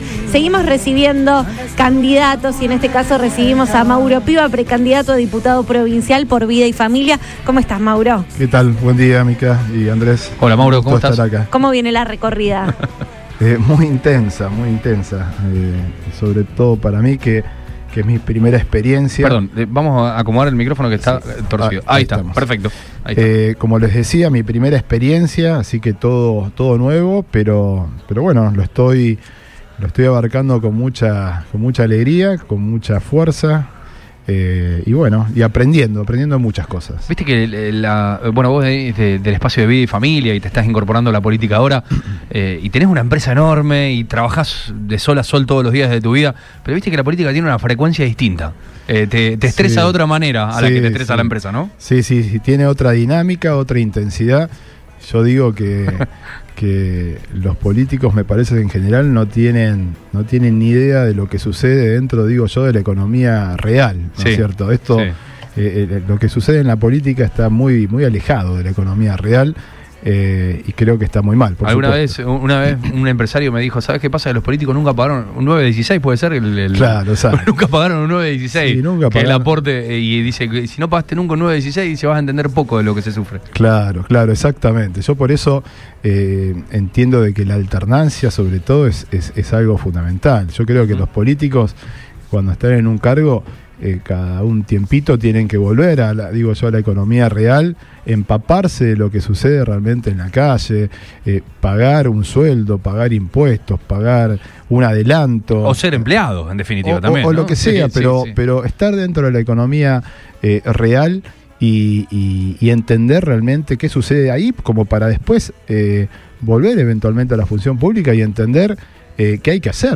En ese contexto, dialogó con el equipo de Lo Mejor de Todo por Radio Boing donde expresó sus ideales de cara a las elecciones.